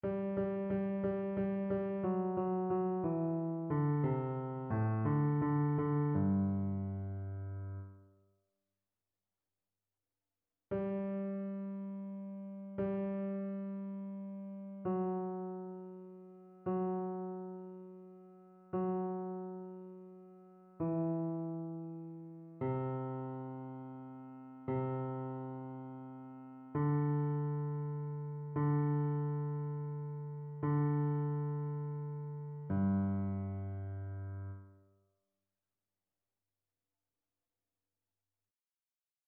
Basse
annee-abc-fetes-et-solennites-dedicace-des-eglises-psaume-83-basse.mp3